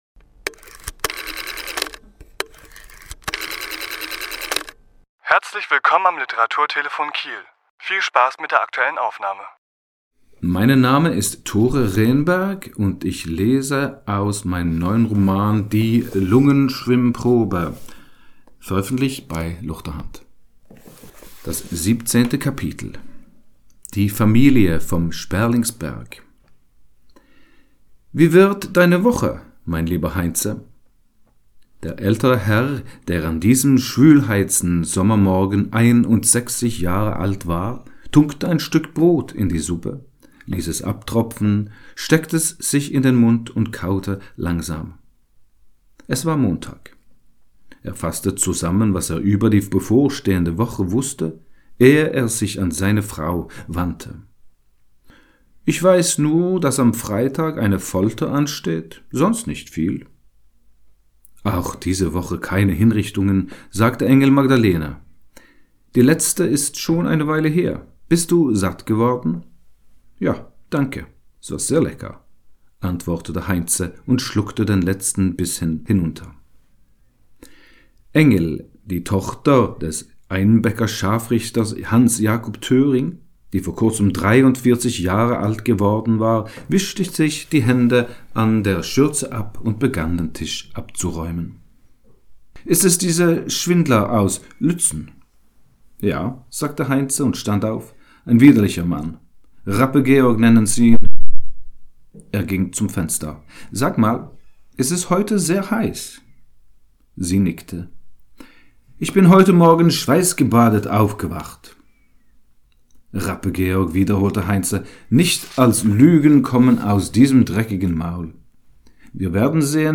Autor*innen lesen aus ihren Werken
Die Aufnahme entstand anlässlich einer Lesung im Literaturhaus S.-H. am 24.10.2024.